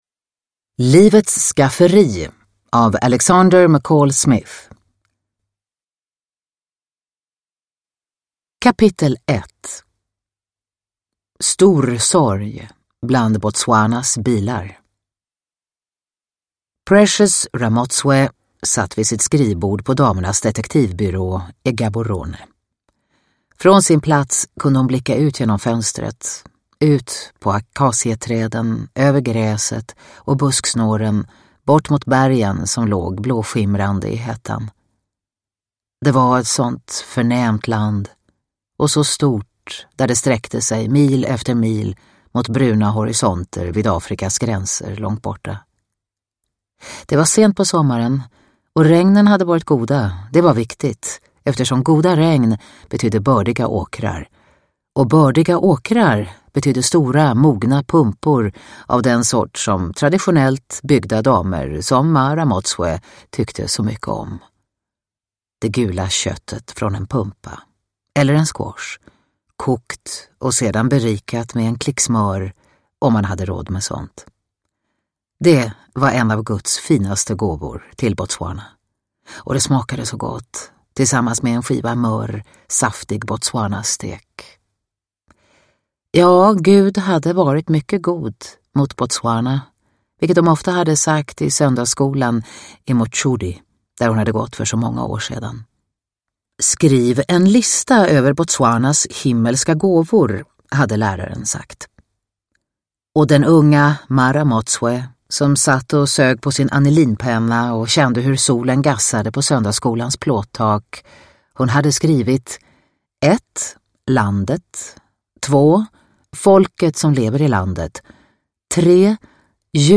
Livets skafferi – Ljudbok – Laddas ner
Uppläsare: Katarina Ewerlöf